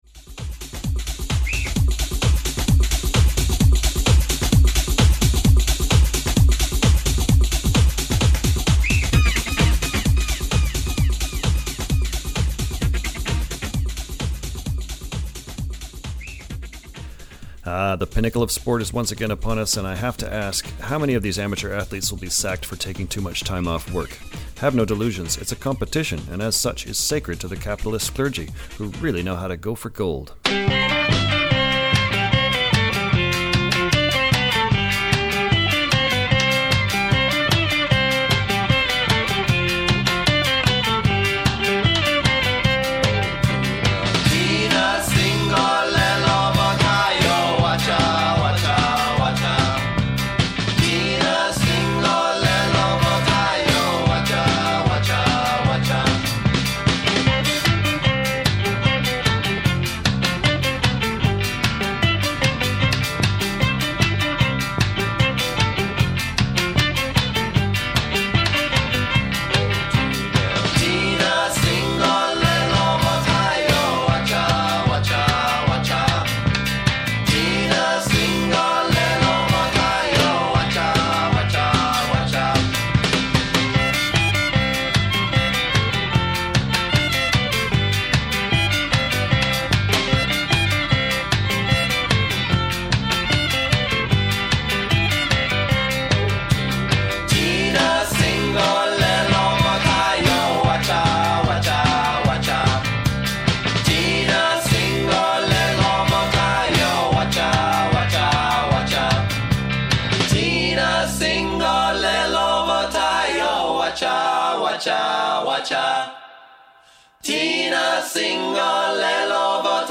Multi-genre mix of rhythm hilights